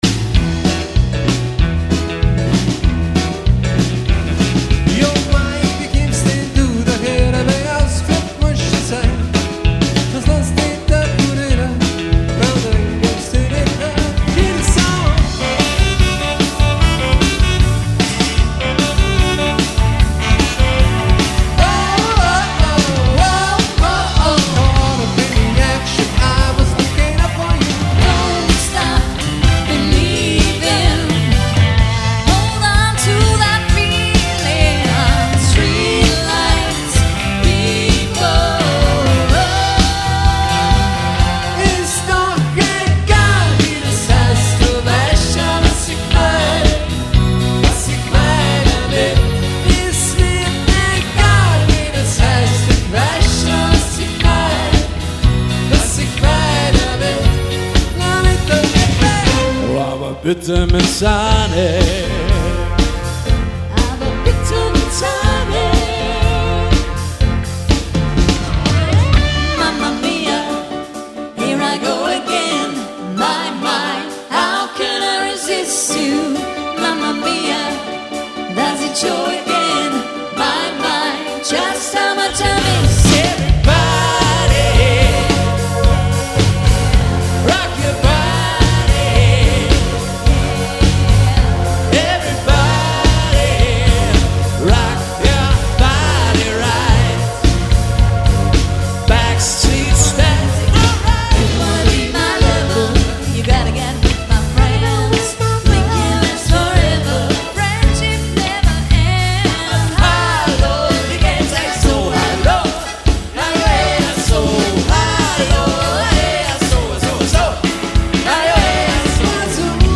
Quartett/Quintett
Ihr Partner für Hochzeitsmusik in Österreich